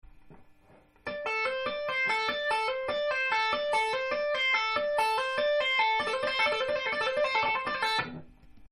Amキーで使える【カンタンライトハンド奏法フレーズ５選】エレキギターで上手そうに見せる速弾きアイデア
ライトハンド奏法フレーズ１
フレーズ１は、伝統的ライトハンドフレーズになります。
スケールはAmペンタトニックスケールを使用しています。